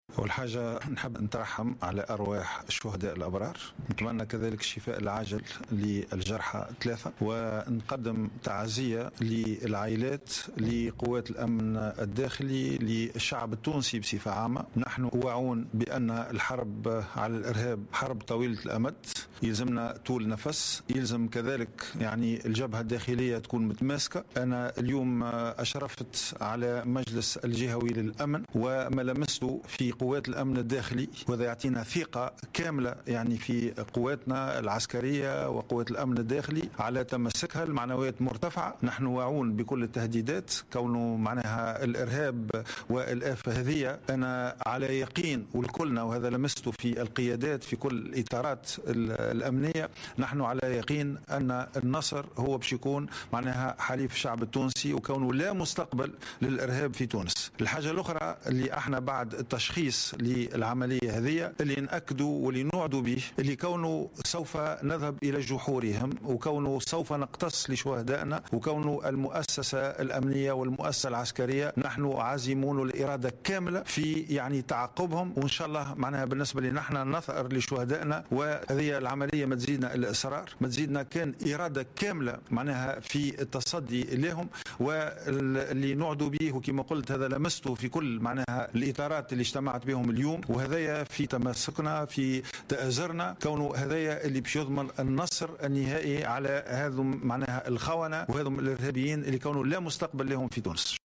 قال غازي الجريبي وزير الداخلية بالنيابة و وزير الدفاع الوطني لدى تحوله إلى ولاية جندوبة التي شهدت عملية ارهابية راح ضحيتها 6 أعوان للحرس الوطني اليوم الأحد أن هذه العملية الإرهابية الغادرة لن تزيد الوحدات الأمنية والعسكرية إلا تمسكا واصرارا على التصدي للإرهاب في تونس.